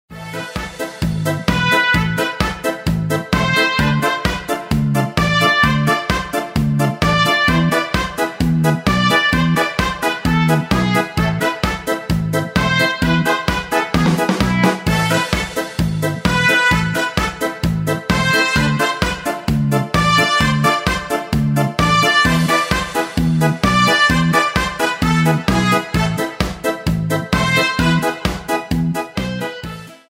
шансон , инструментальные , без слов
аккордеон